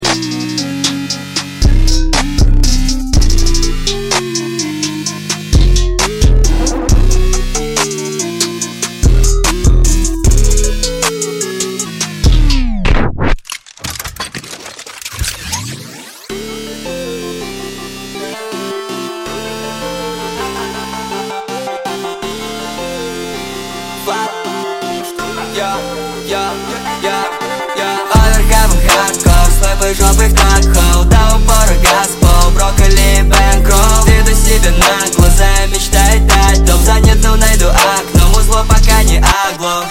Громкие Рингтоны С Басами
Рэп Хип-Хоп Рингтоны